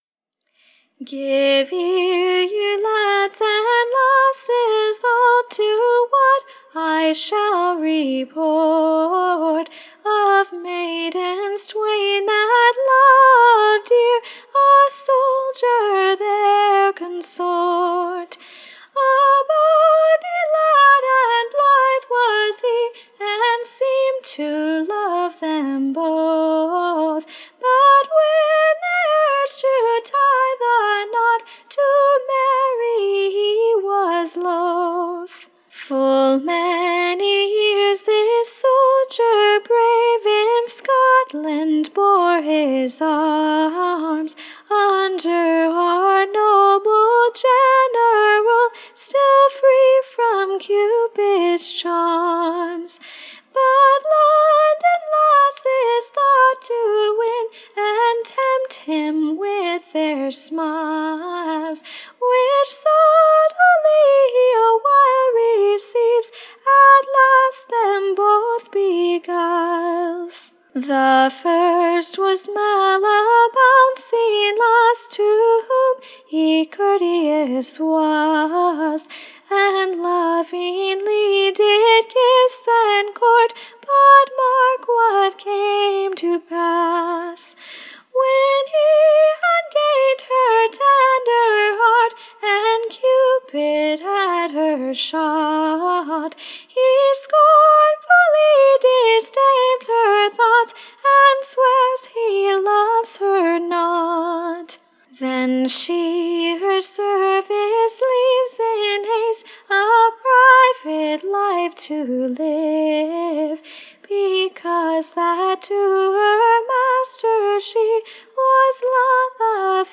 Ballad